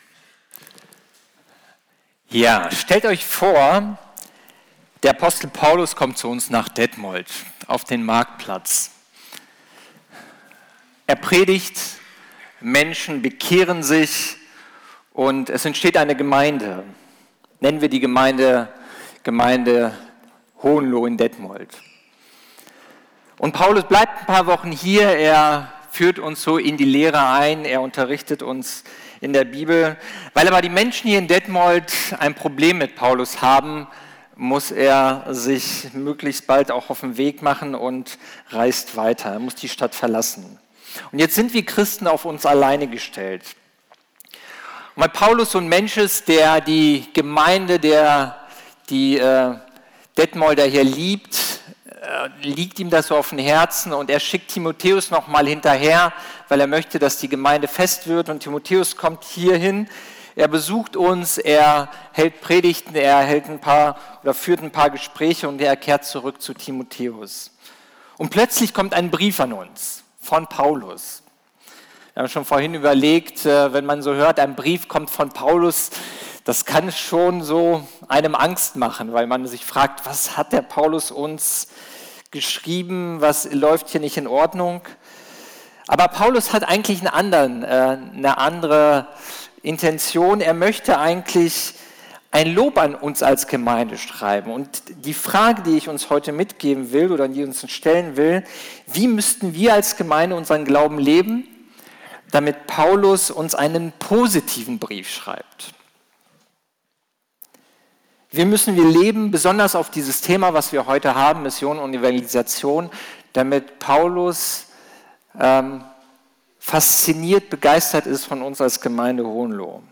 Predigten der Gemeinde